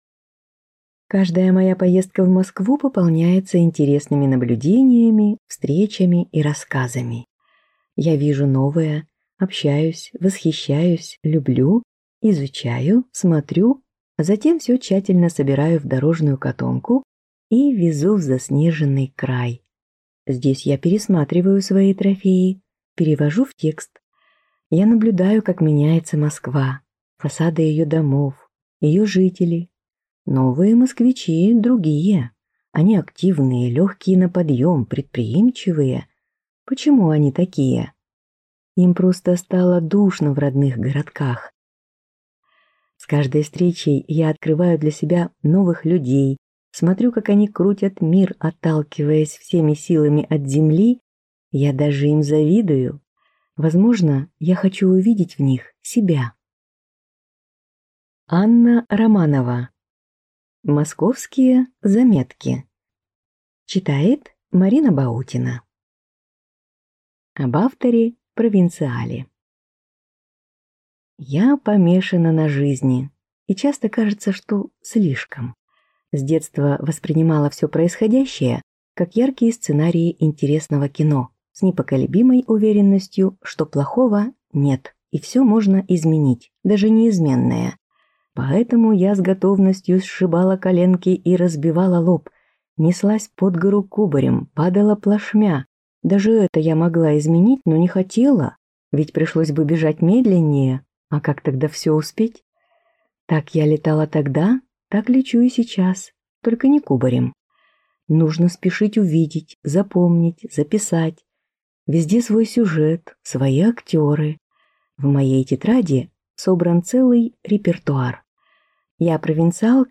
Аудиокнига Московские заметки | Библиотека аудиокниг